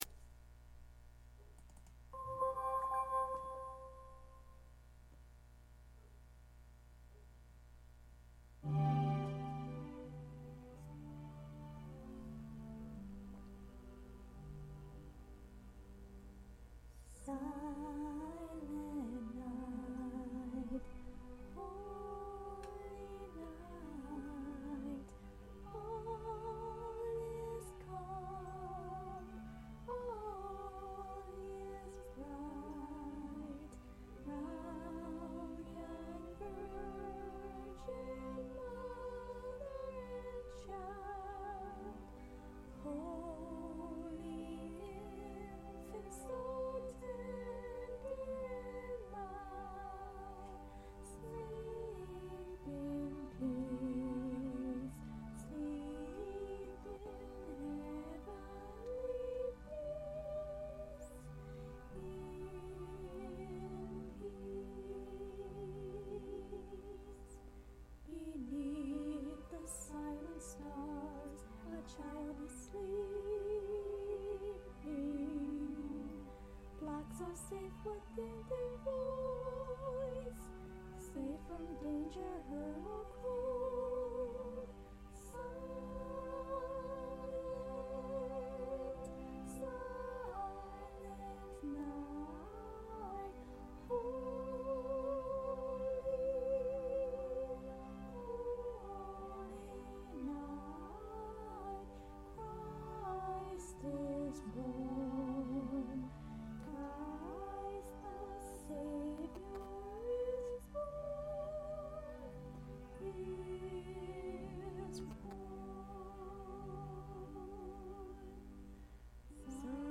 me singin a different version of silent night with a singsnap karaoke member